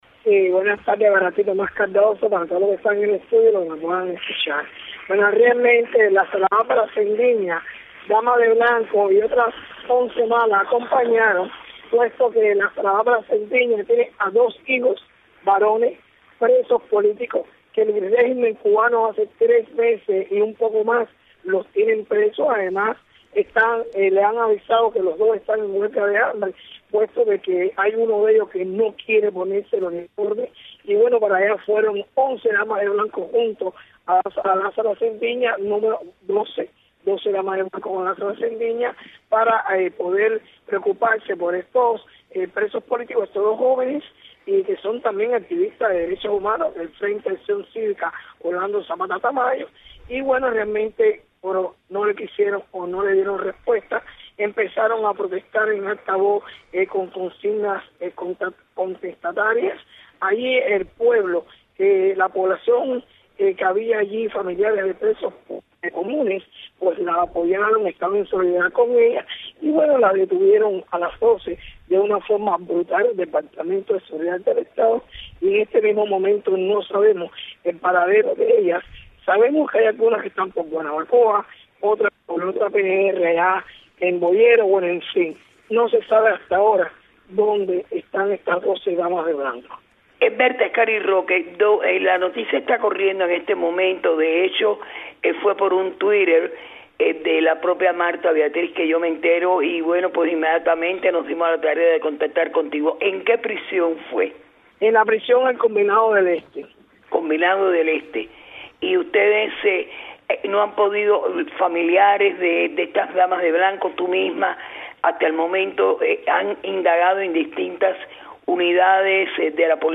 Declaraciones de Berta Soler a Radio Martí